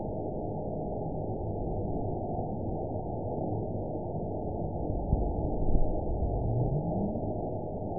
event 917660 date 04/11/23 time 20:50:36 GMT (2 years, 1 month ago) score 9.58 location TSS-AB04 detected by nrw target species NRW annotations +NRW Spectrogram: Frequency (kHz) vs. Time (s) audio not available .wav